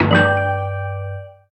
throwoffstun.ogg